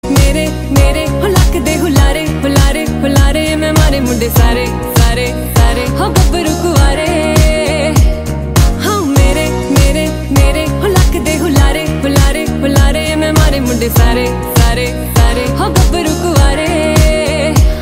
Category Punjabi